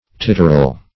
titterel - definition of titterel - synonyms, pronunciation, spelling from Free Dictionary Search Result for " titterel" : The Collaborative International Dictionary of English v.0.48: Titterel \Tit"ter*el\, n. The whimbrel.
titterel.mp3